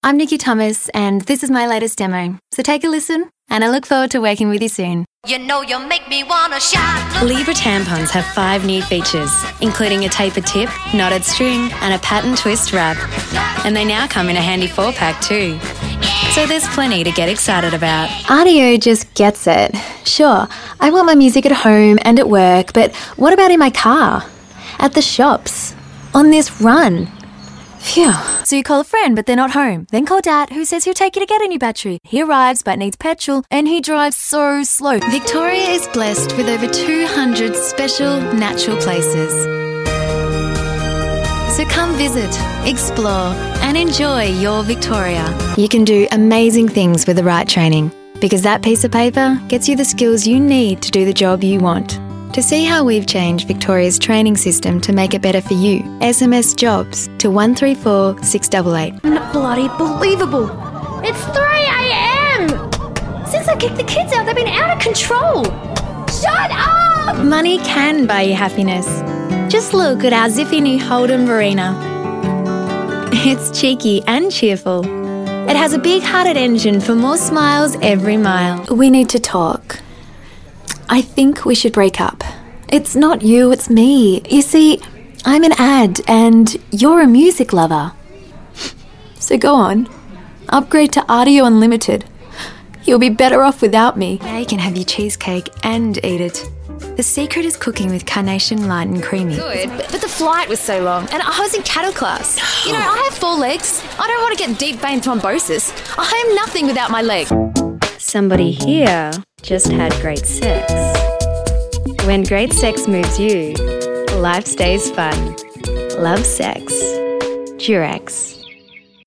Friendly, upbeat
englisch (australisch)
Sprechprobe: Sonstiges (Muttersprache):
Female VO Artist